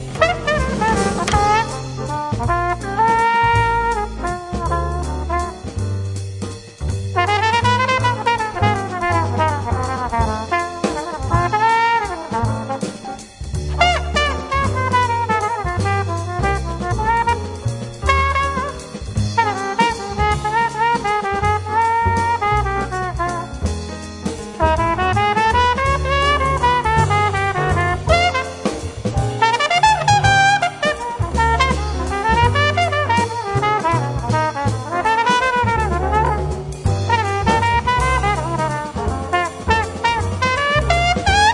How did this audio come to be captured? Recorded at the Red Gables Studio, September 26th 2006